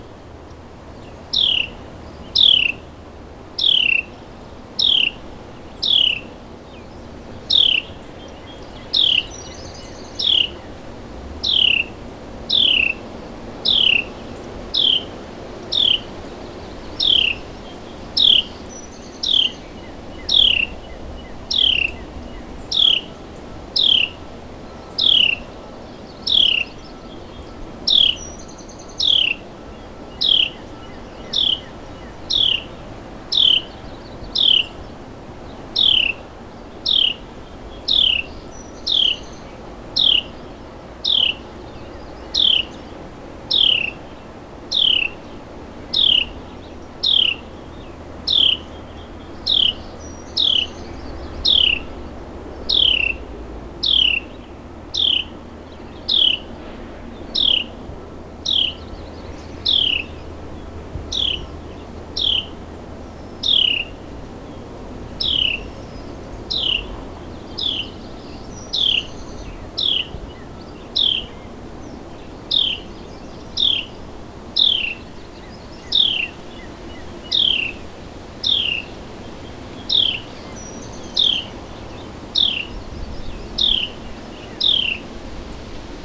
And to cheer you all on, here’s the “cheer call” of the Carolina Wren. His message is clear, just like the cardinal’s. I recorded the loud songster at Money Point in Chesapeake.
carolina-wren-cheer.wav